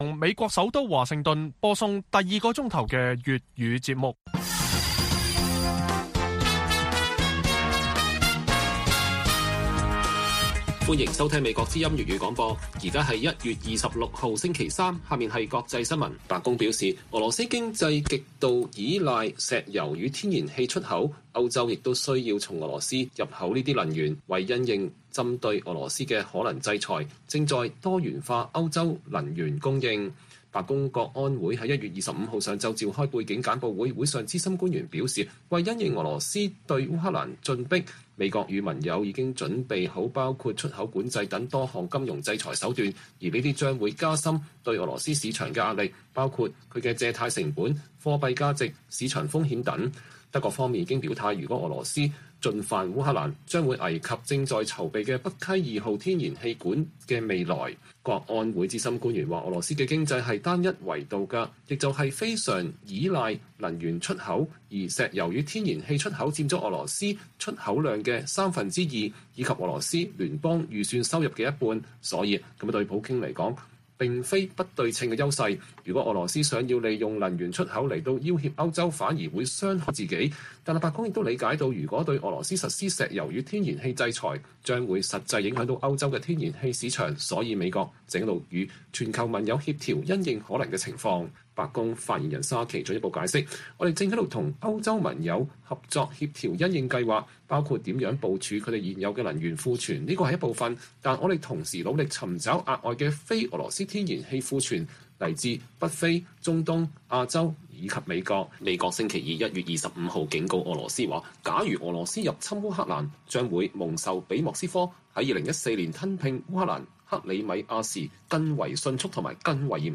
粵語新聞 晚上10-11點: 白宮說為制裁俄羅斯做準備，正在多元化歐洲天然氣供應